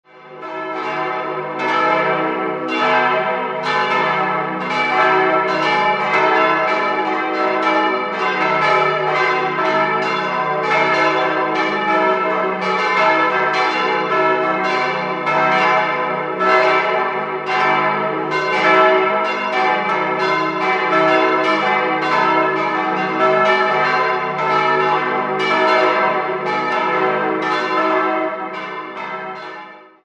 Idealquartett: e'-g'-a'-c''
bell
Die sehr leichtrippigen Euphonglocken stammen aus der alten Pfarrkirche weiter unten im Ort, deren Turm heute keine Glocken mehr trägt. Aus den schmalen Schallschlitzen des niedrigen Turmes erklingt dieses Geläute in kräftiger, aber dennoch nicht unangenehmer Lautstärke.